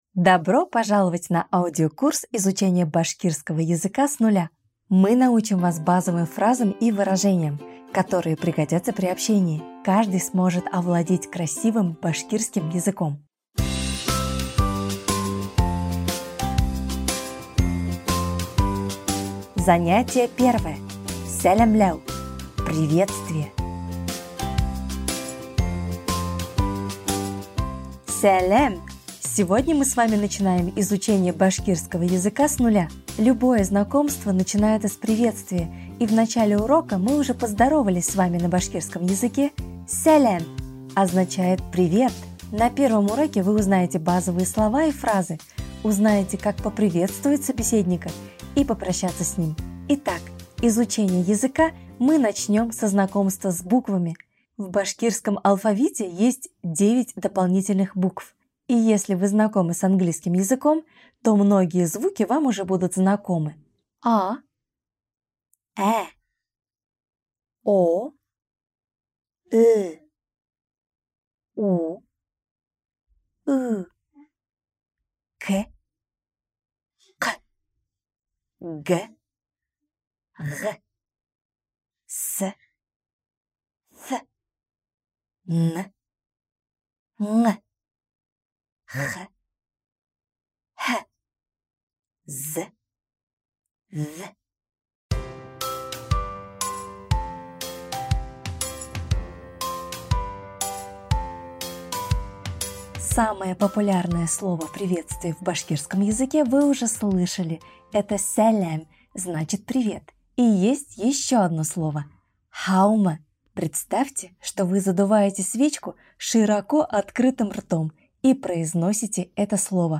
Башкирский с нуля (аудиокурс): Урок 1 – Сәламләү (Приветствие)